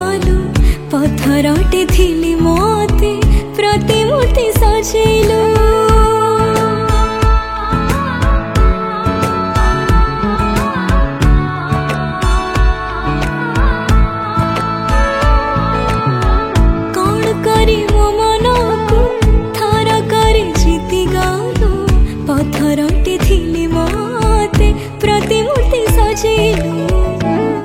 Odia Album Ringtones
Romantic song